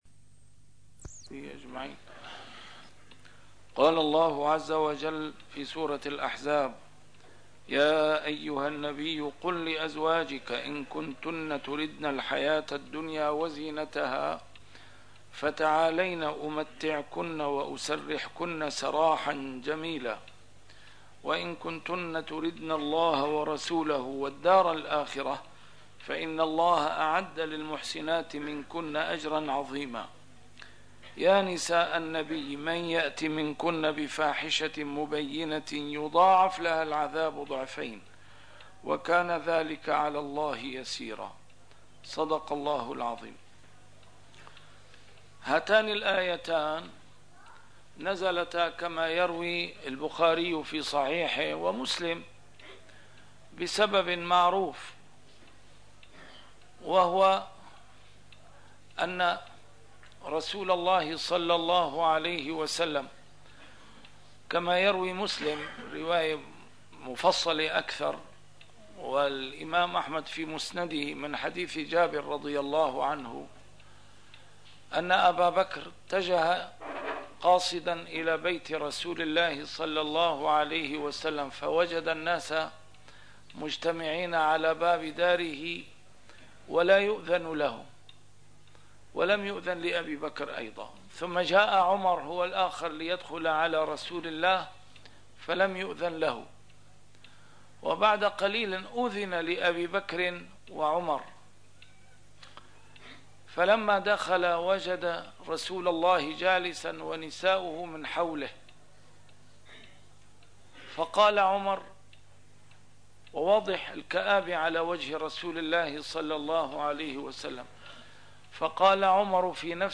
A MARTYR SCHOLAR: IMAM MUHAMMAD SAEED RAMADAN AL-BOUTI - الدروس العلمية - تفسير القرآن الكريم - تسجيل قديم - الدرس 371: الأحزاب 28-29